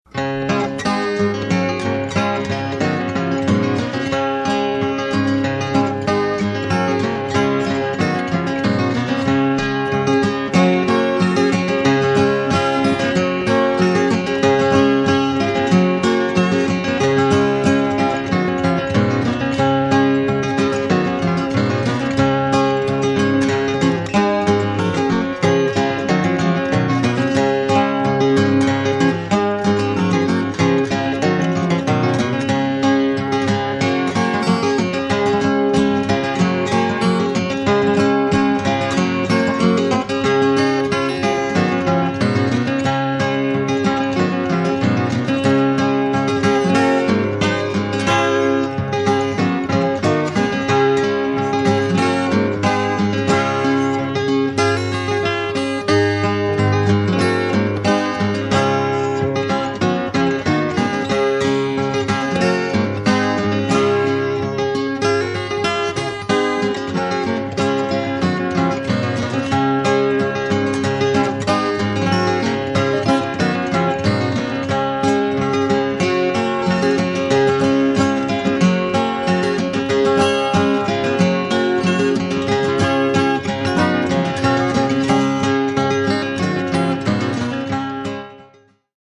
Recorded 1971 in San Francisco and Cambria, USA
remastered from the original tapes